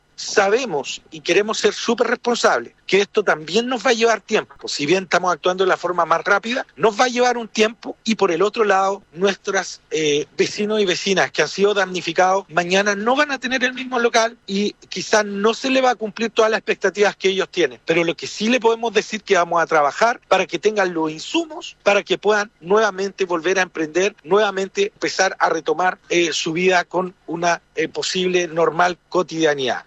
El alcalde de Ancud, Andrés Ojeda, confirmó que 60 locales comerciales resultaron quemados y 10 propiedades dañadas, dejando unos 300 damnificados.
cuna-alcaldd.mp3